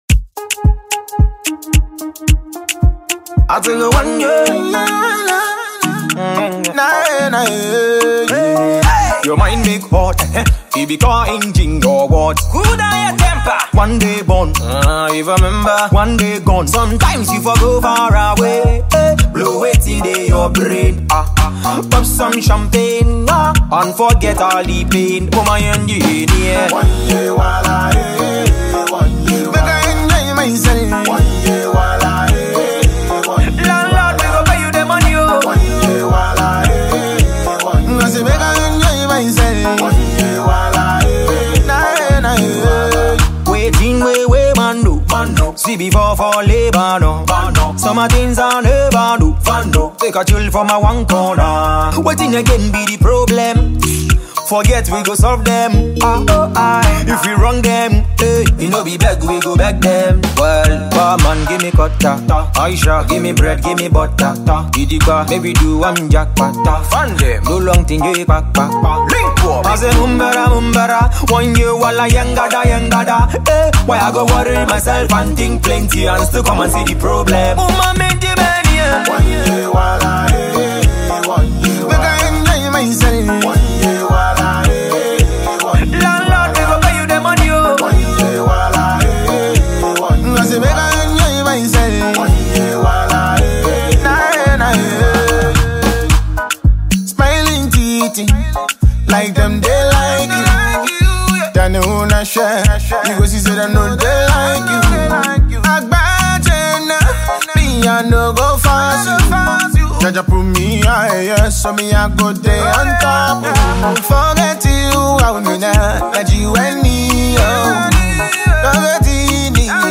a danceable tune for the festive season